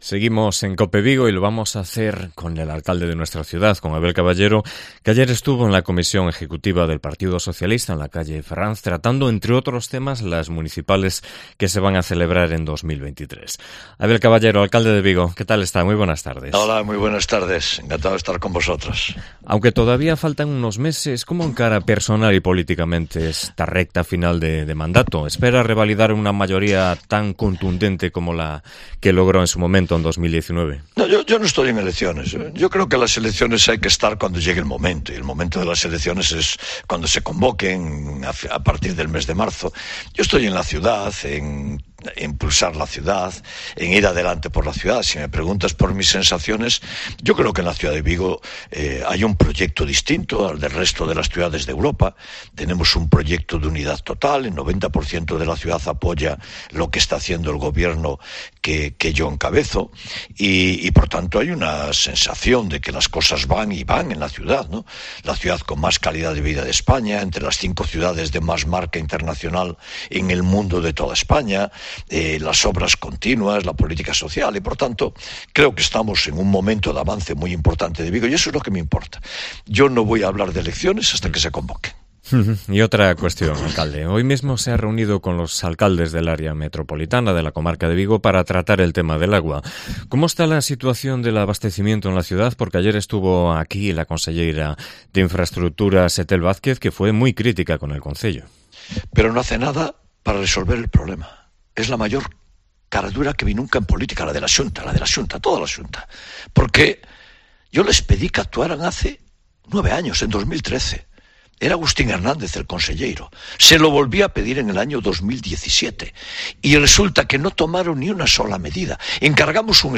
ENTREVISTA
Entrevista con Abel Caballero, alcalde de Vigo